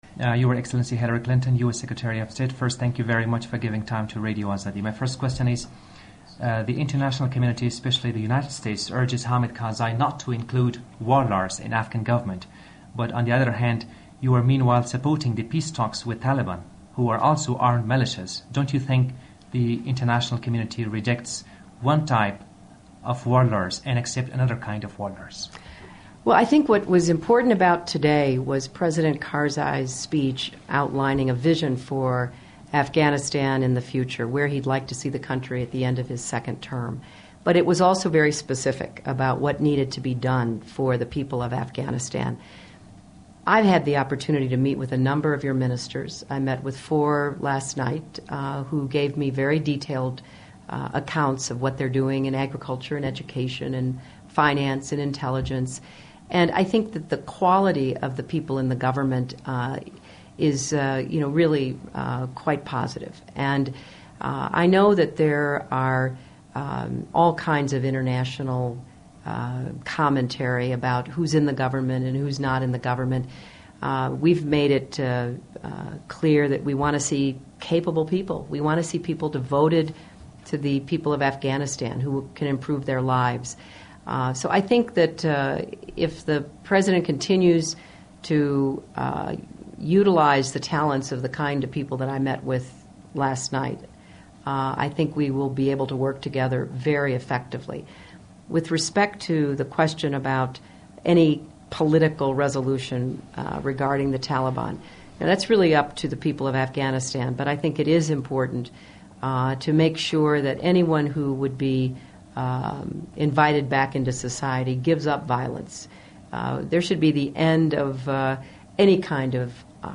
Interview With U.S. Secretary Of State Hillary Clinton
U.S. Secretary Of State Hillary Clinton sat down with RFE/RL's Radio Free Afghanistan in Kabul to discuss the new Afghan government following the inauguration of President Hamid Karzai.